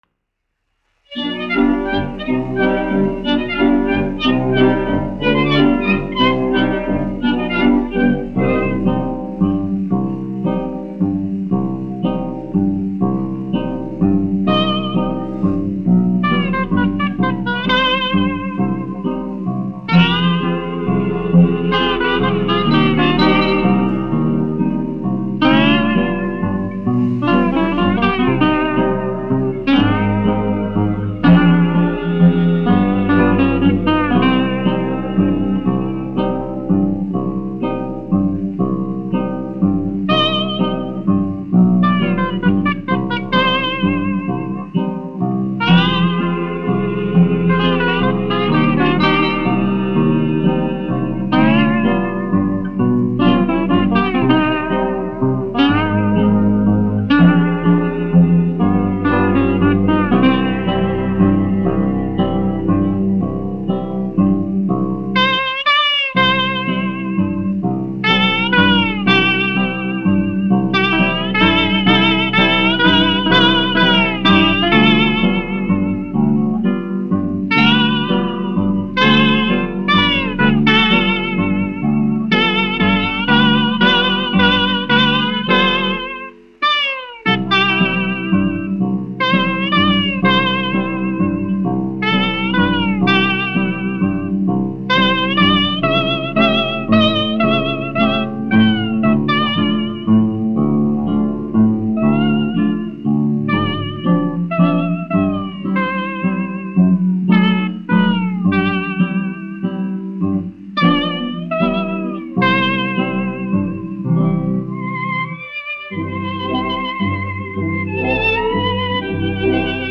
1 skpl. : analogs, 78 apgr/min, mono ; 25 cm
Populārā instrumentālā mūzika
Valši
Skaņuplate